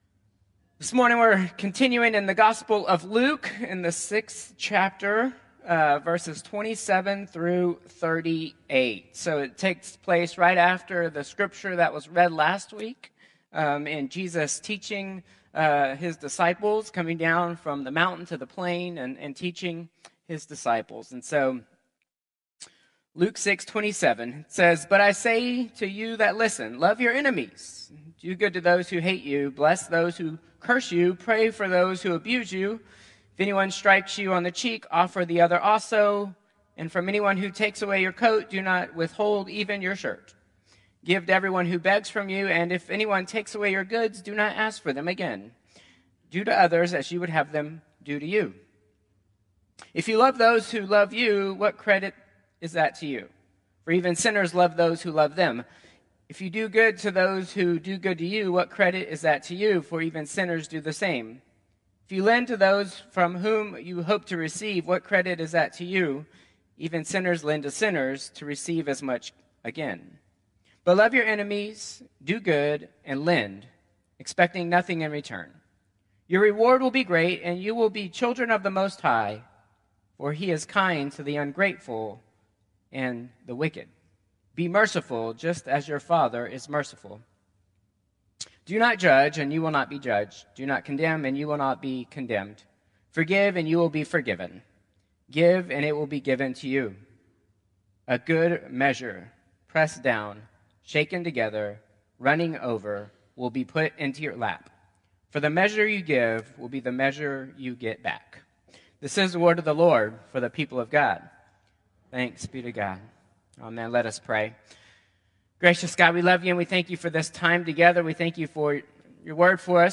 Contemporary Service 2/23/2025